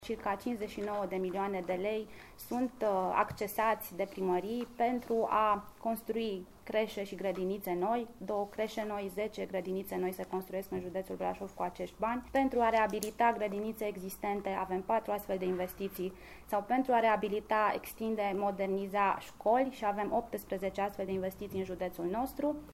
Guvernul spune că sprijină educația și le-a oferit ocazia primarilor să acceseze fonduri guvernamentale pentru clădirile în care se desfășoară procesul de învățământ. Deputatul PSD, Roxana Mînzatu: